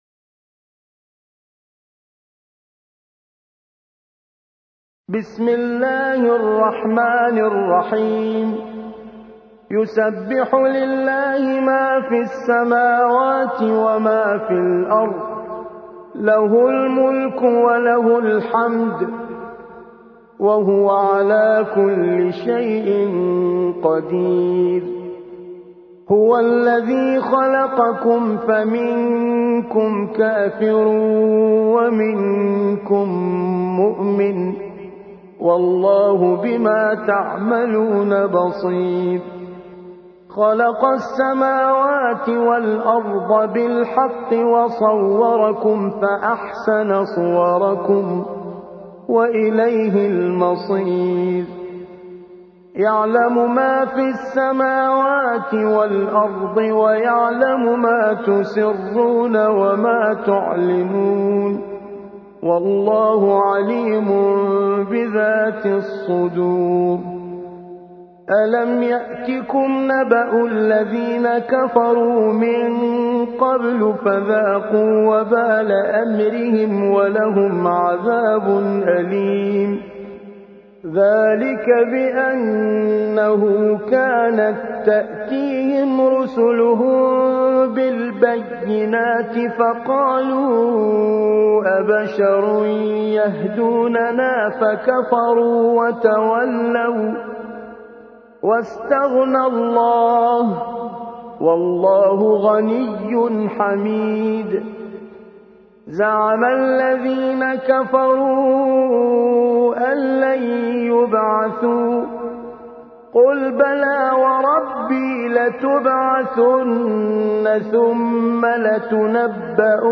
64. سورة التغابن / القارئ